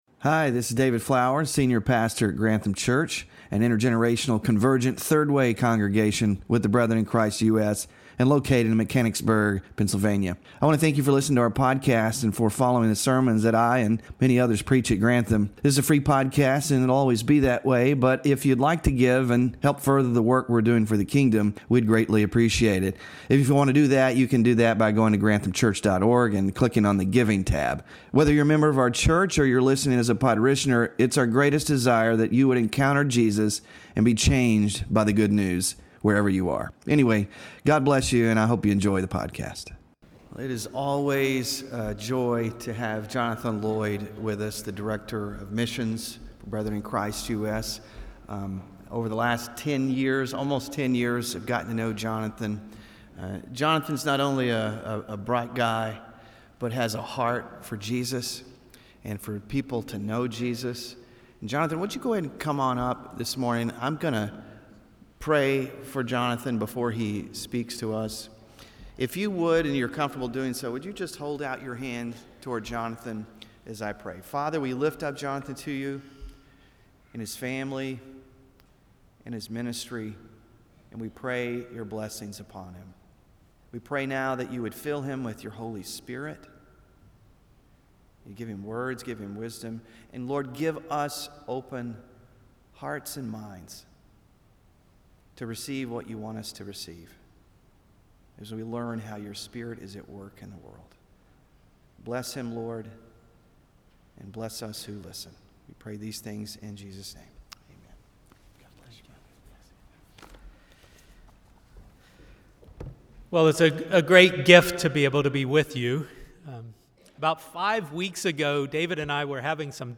Sermon Focus: The call of God for His people is a call to become like foreigners.